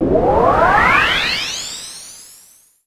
MB Trans FX (3).wav